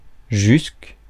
Ääntäminen
US : IPA : [ʌn.ˈtɪl]